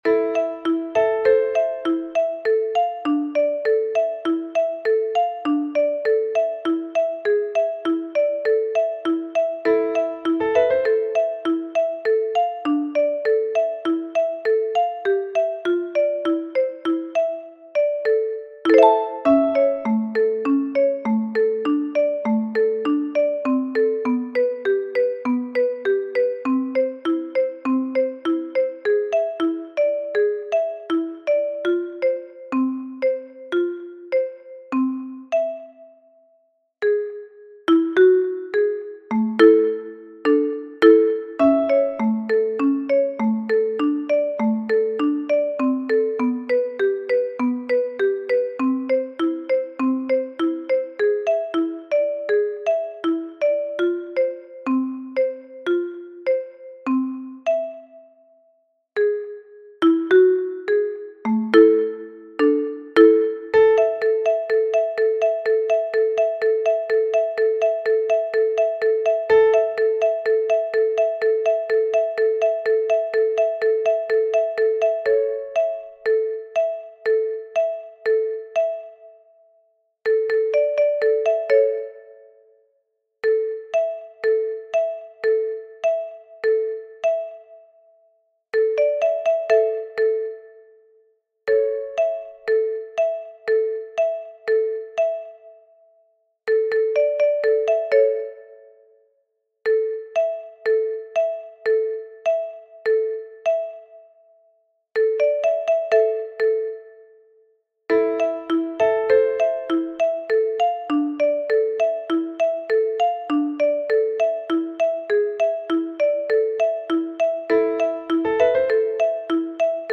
Hungarian Dance accompaniment
Finally, here you have got the sound file corresponding to the accompaniment.
Hungarian_Dance_No._5_in_G_Minor_acomp.mp3